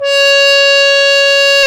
Index of /90_sSampleCDs/Roland LCDP12 Solo Brass/BRS_French Horn/BRS_Mute-Stopped